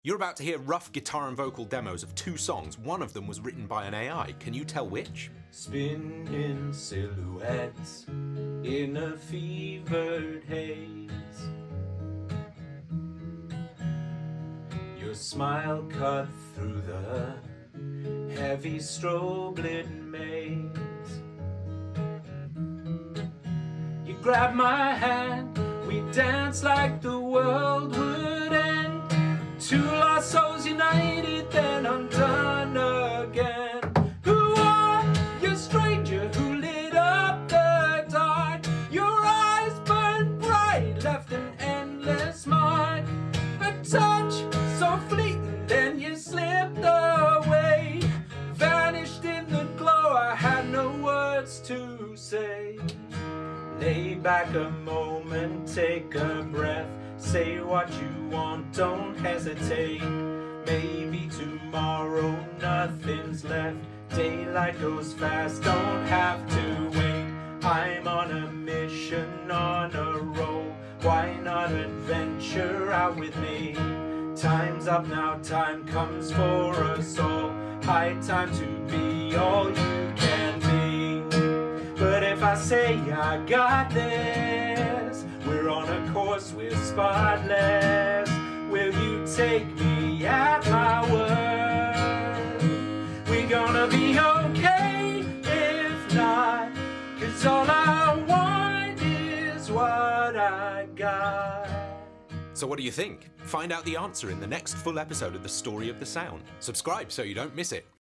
Both of these videos are real, and feature a real live demo performance of a song. But one of the songs was composed by a human, and the other was written, music, lyrics, and all, by an AI tool, with no human creative input at all except for a few words typed in a prompt box.